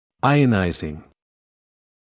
[‘aɪənaɪzɪŋ][‘айэнайзин]ионизирующий; инговая форма от to ionize